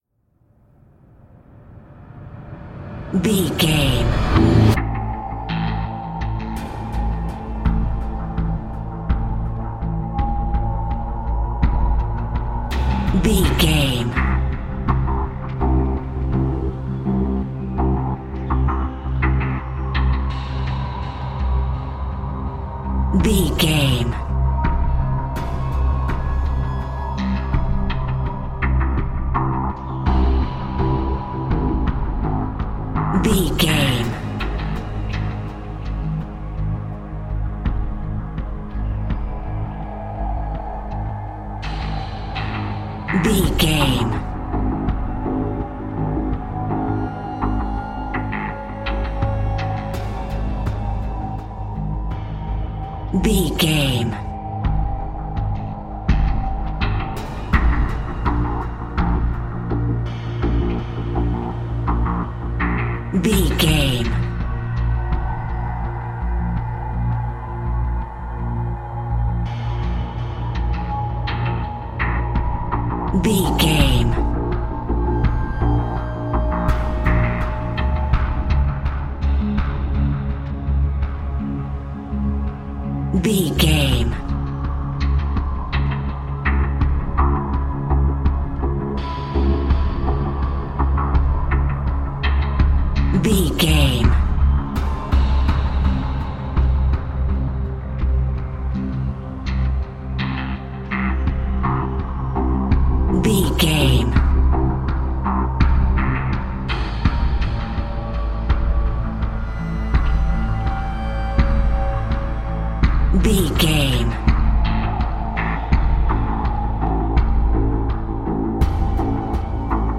Thriller
Aeolian/Minor
synthesiser
drum machine